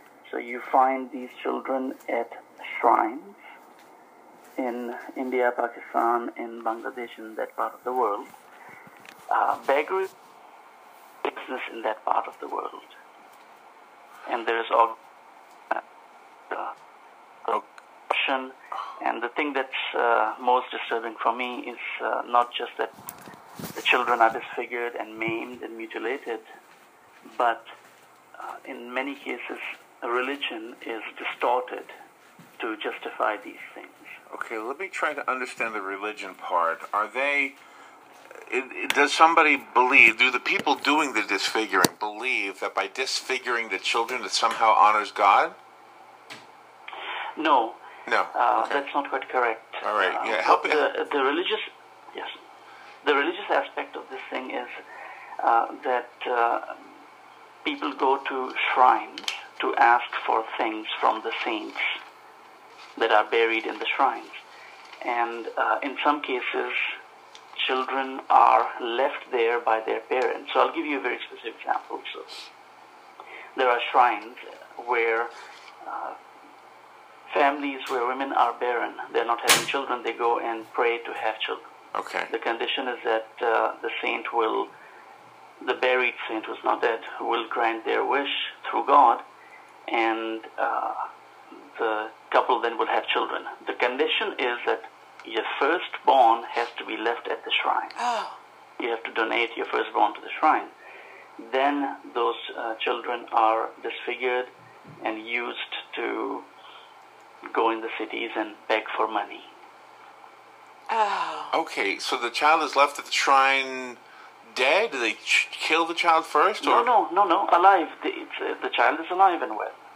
The interview was conducted on Sunday, October 20, 2013 on the topic ‘Should Religion be a Business?’.